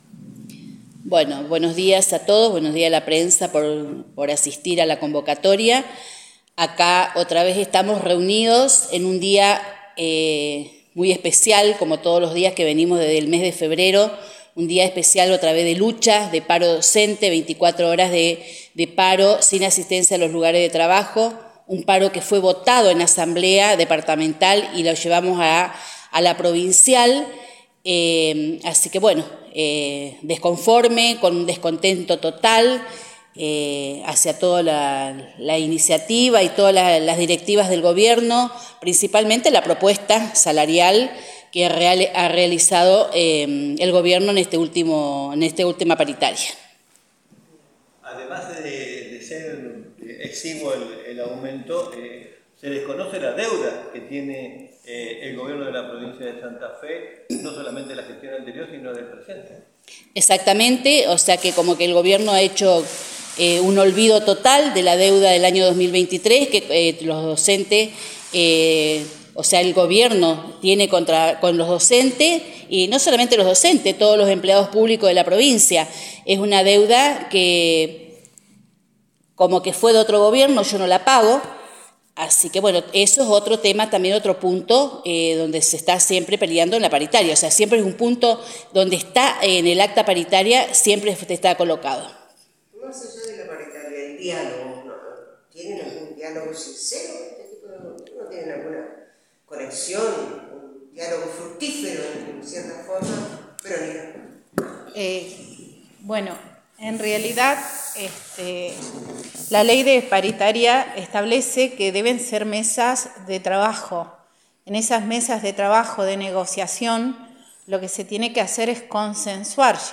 AMSAFE y SADOP brindaron una conferencia de prensa explicando nuevamente las medidas tomadas por ambos gremios